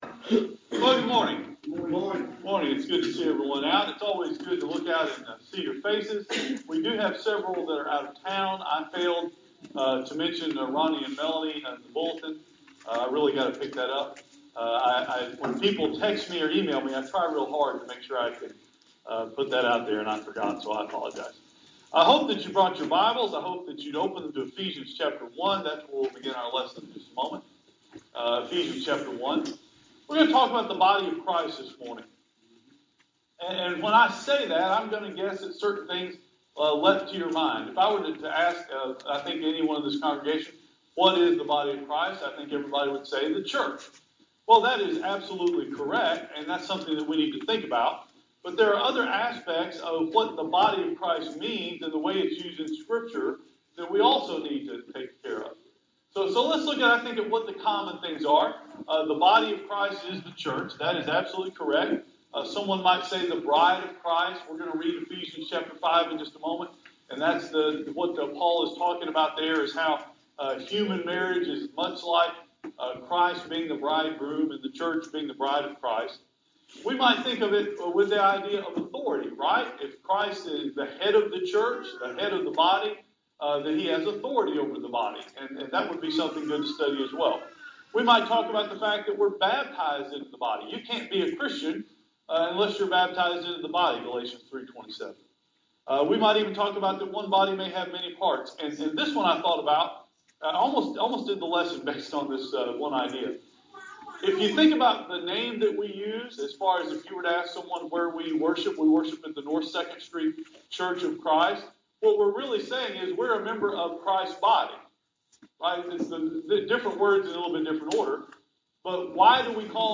The audio quality of this is not the best. We had to pull the audio from the Facebook Live feed.